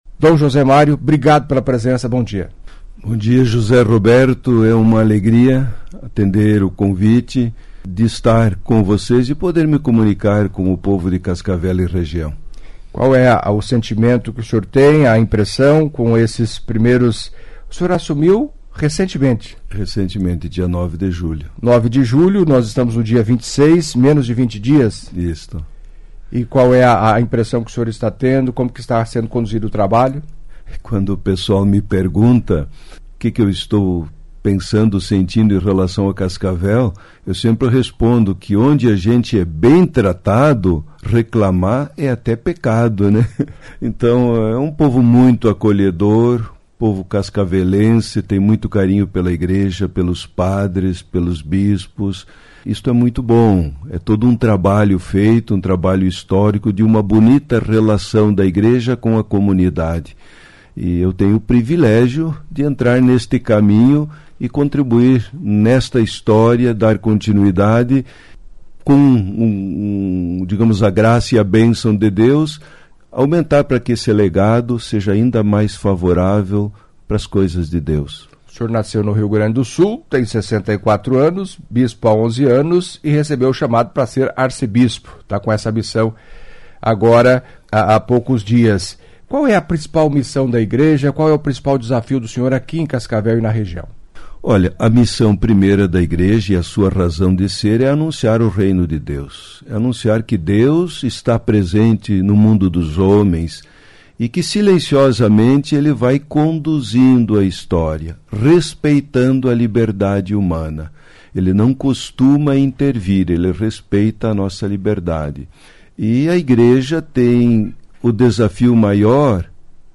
Em entrevista à CBN nesta sexta-feira (26) Dom José Mário, que recentemente assumiu como arcebispo de Cascavel, tomou posse no dia 9 de junho, falou das impressões nesse curto período e da missão que desenvolve para o fortalecimento da Igreja Católica.